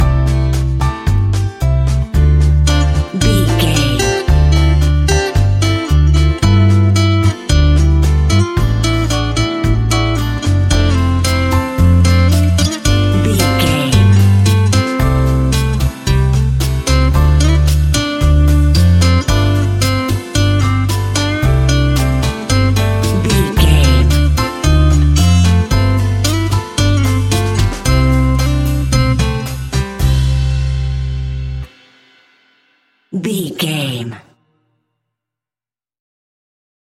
An exotic and colorful piece of Espanic and Latin music.
Uplifting
Ionian/Major
flamenco
maracas
percussion spanish guitar